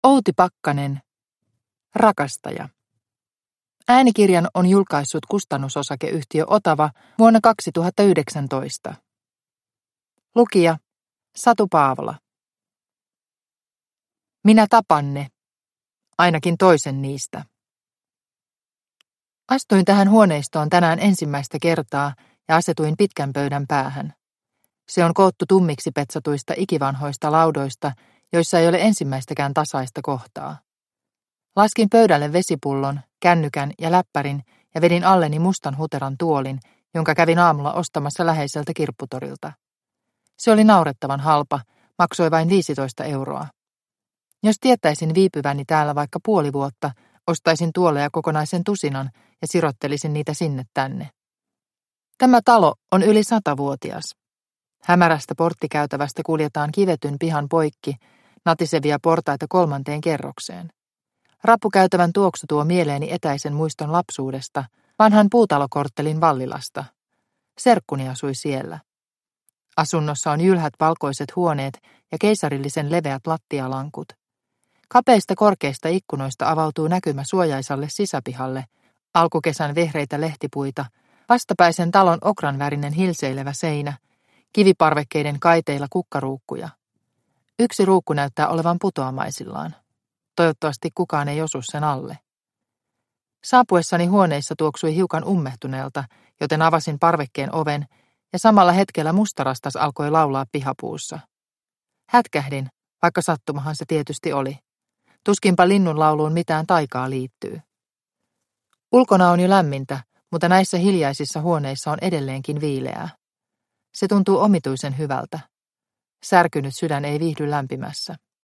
Rakastaja – Ljudbok – Laddas ner